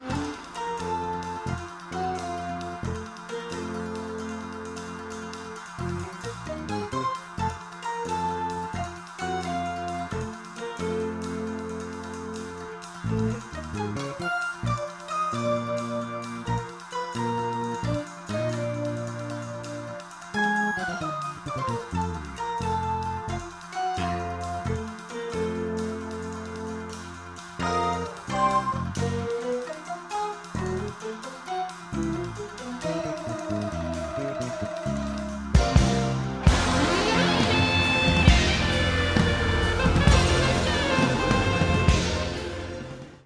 piano and synths
guitar
saxophone
bass
drums